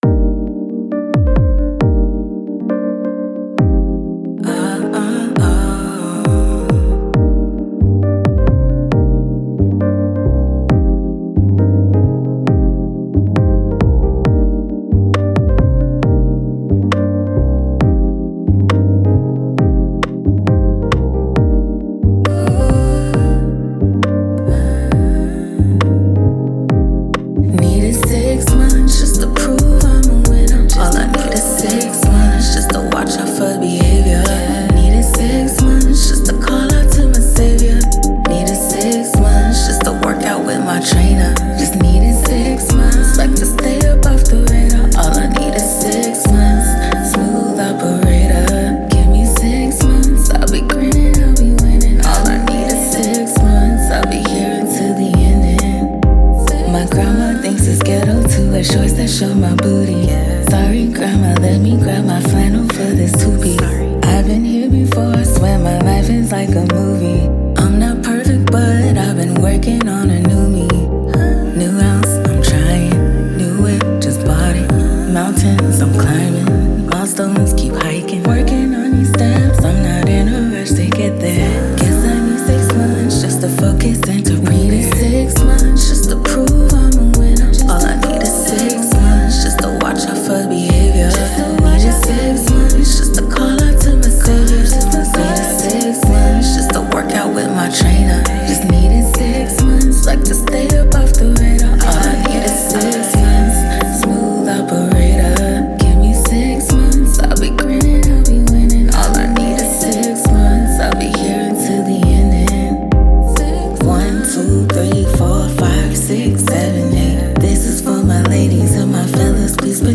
RnB
New soulful r&b vibes from Dallas
grown and sexy songstress vocals